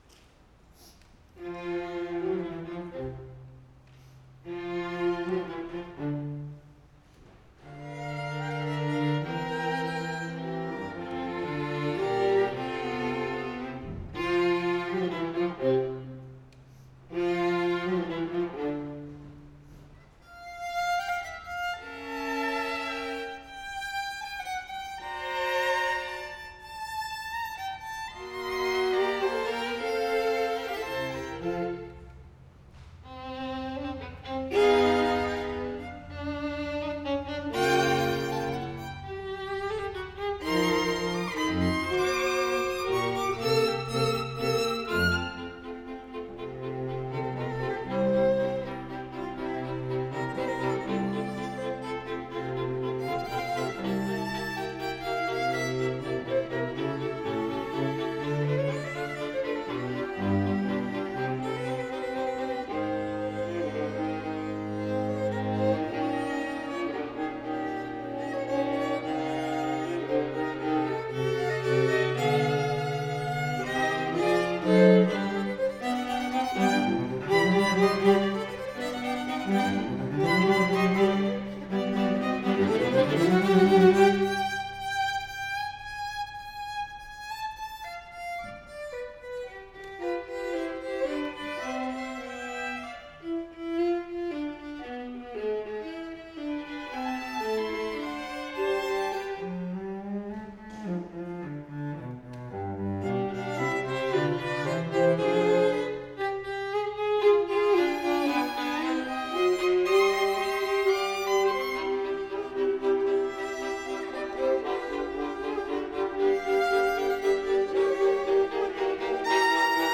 the students
Chamber Groups
Allegro con brio